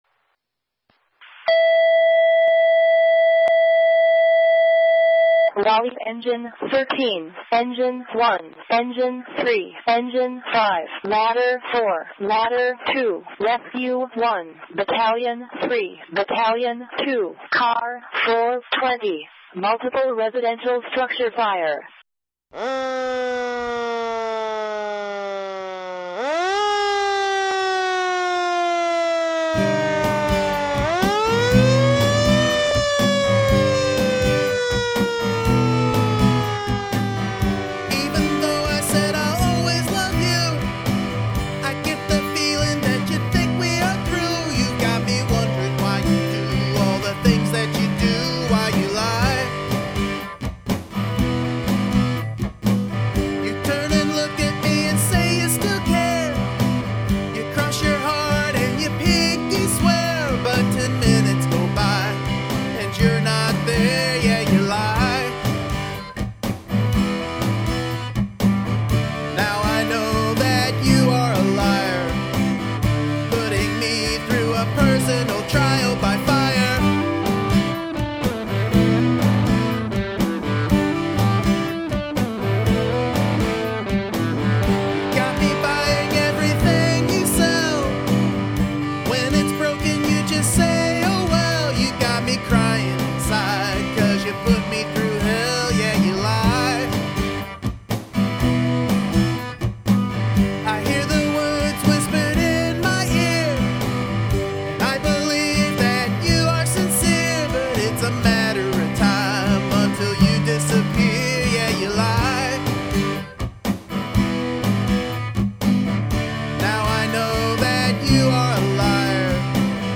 Incorporate sirens